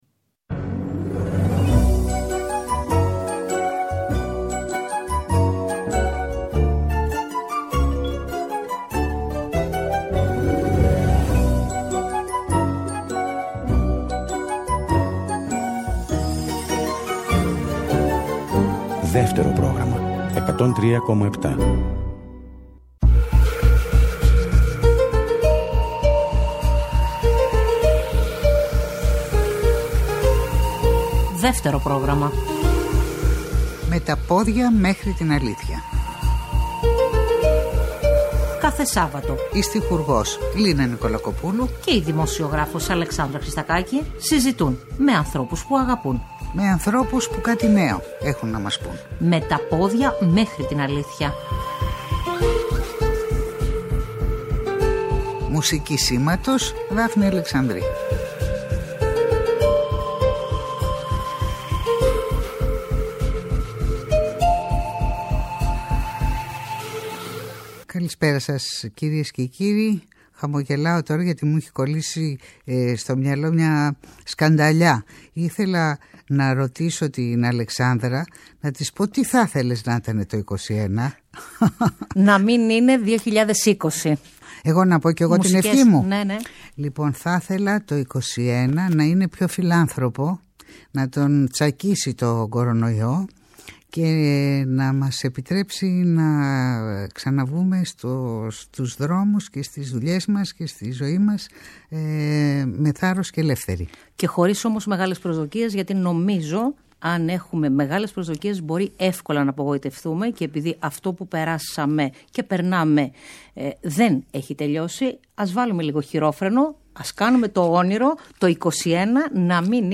Στην εκπομπή ακούγονται ηχητικά ντοκουμέντα με την δική του φωνή για την μυθιστορηματική του ζωή, από το αρχείο της ΕΡΤ και του Mega .